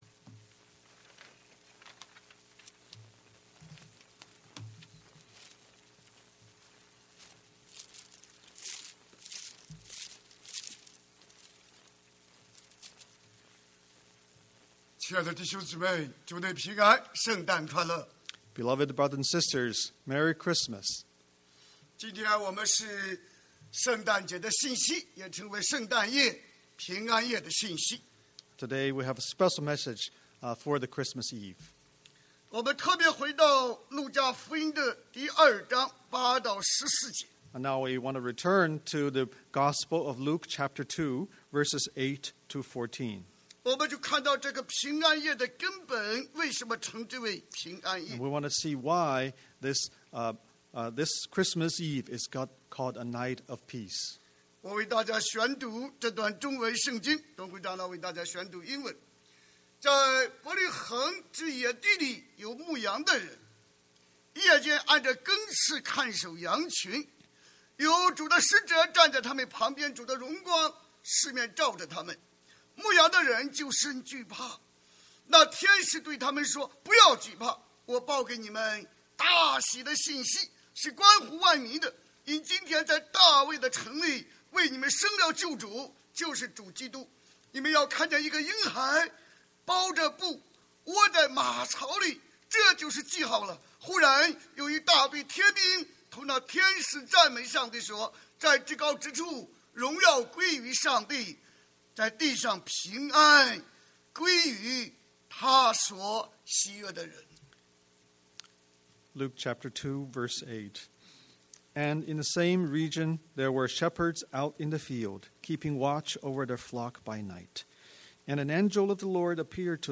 God’s Greatest Gift (Christmas Eve Sermon)